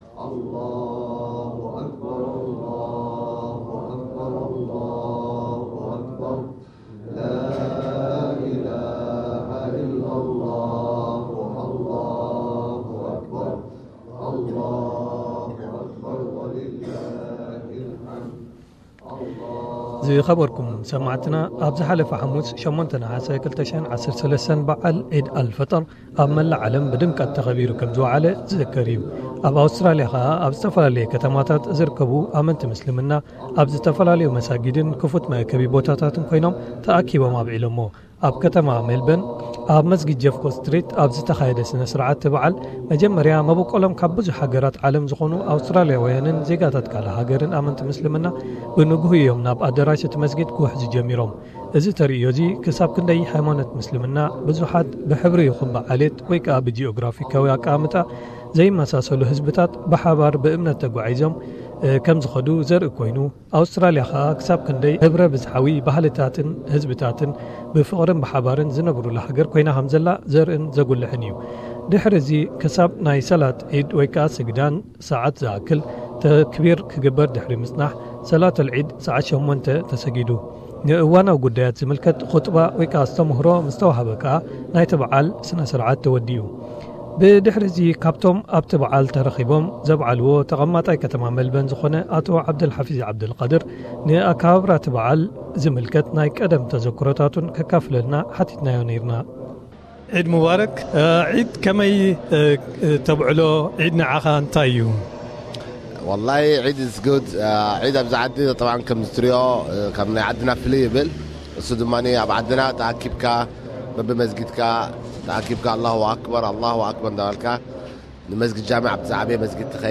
EED El Fitr 2013 REPORT